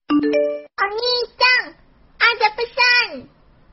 Nada dering pesan masuk Anime
Kategori: Nada dering
nada-dering-pesan-masuk-anime-id-www_tiengdong_com.mp3